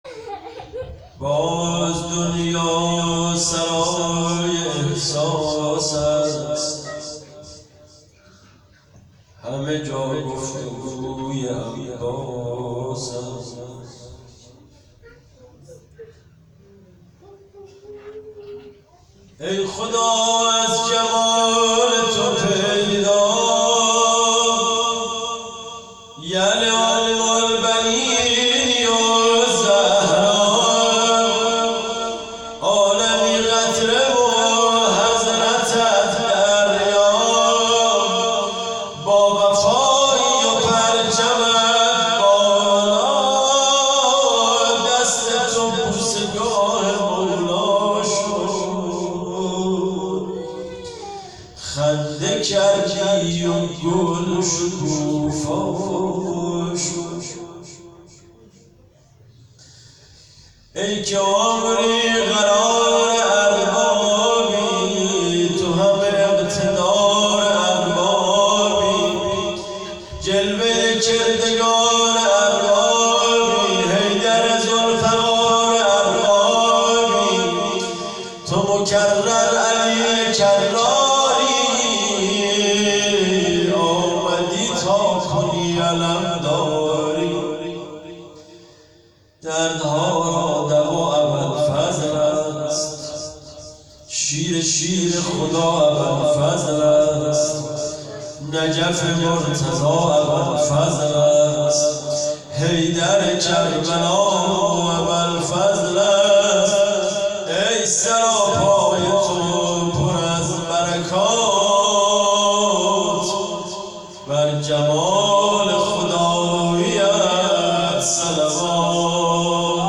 شب میلاد سرداران کربلا_بخش اول مدح